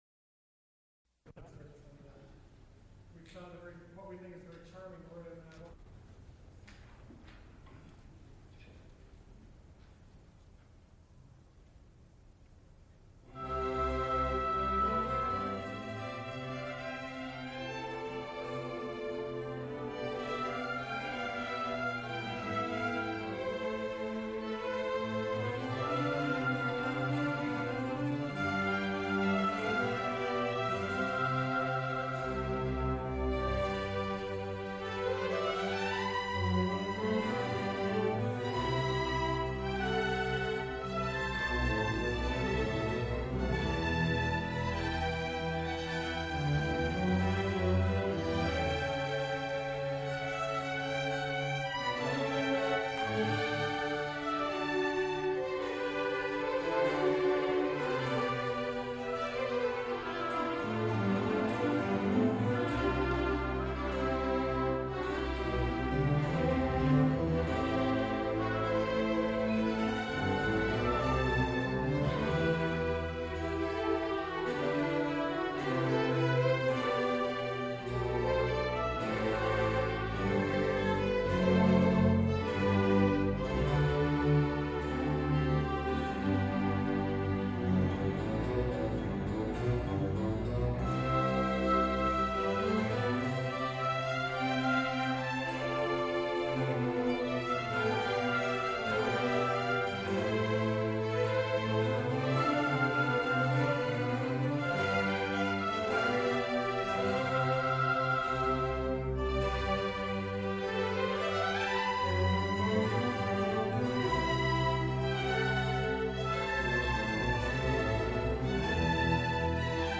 Laugharne : for soprano, tape and orchestra.
Recorded live October 11, 1977, Schenley Hall, University of Pittsburgh.
Extent 3 audiotape reels : analog, quarter track, 7 1/2 ips ; 7 in.
Songs (High voice) with orchestra